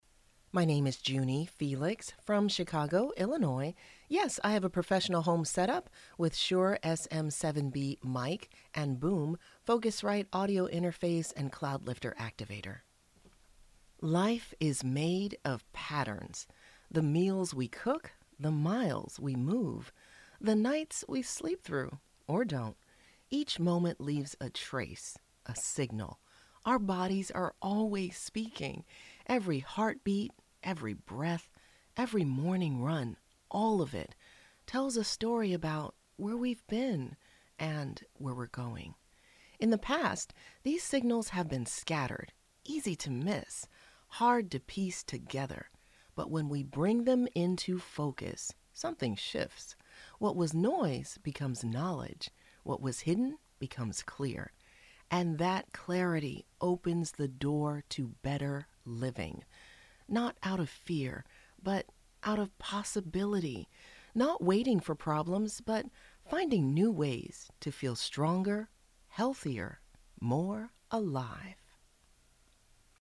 General American